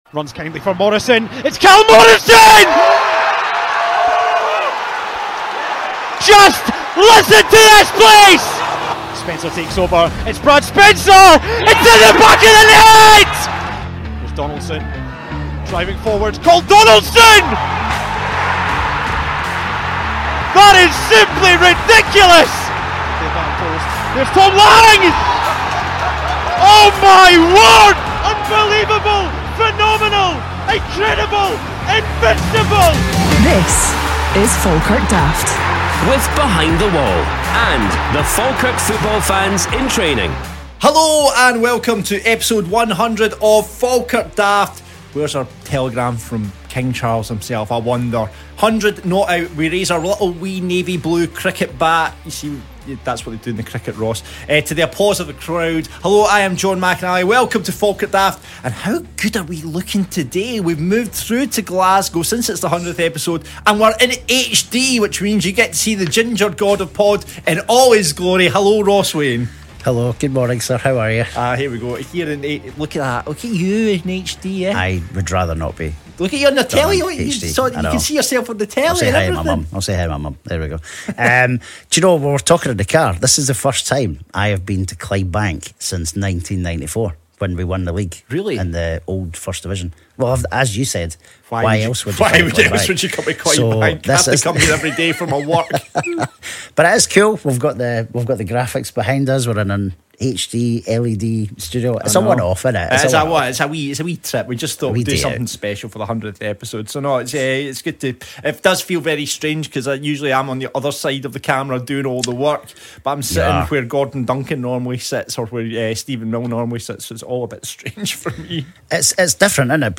recording it in a proper TV studio